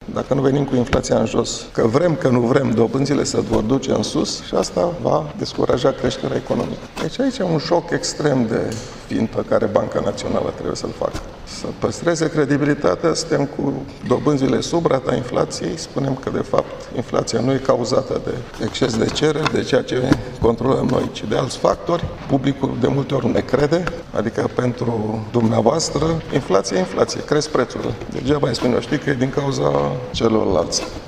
Inflaţia va ajunge la 3,5% la sfârşitul anului, iar apoi va coborî spre 3 procente, a declarat, astăzi, guvernatorul Băncii Naţionale, Mugur Isărescu. El a explicat, la o conferinţă de specialitate, că BNR urmăreşte evoluţia inflaţiei, întrucât aceasta afectează dobânzile.